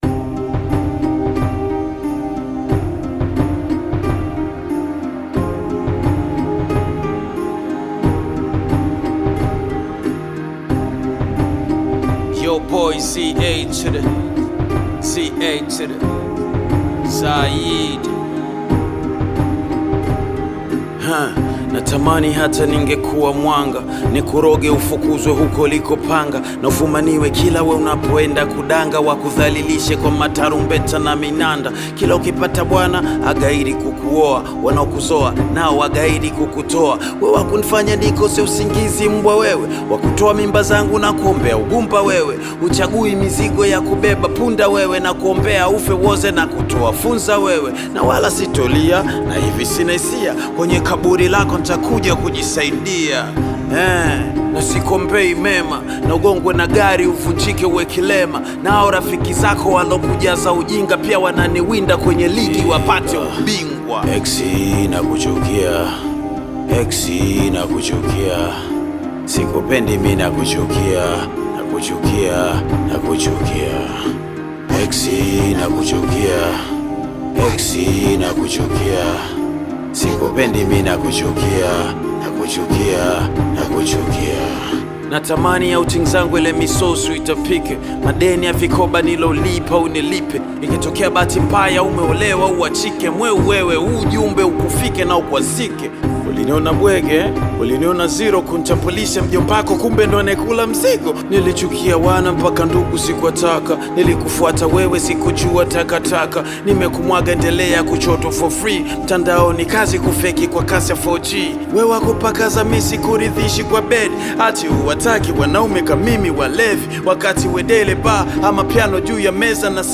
melody song